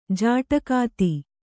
♪ jāṭakāti